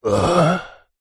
Мужской вздох уф